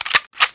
weapgrab.mp3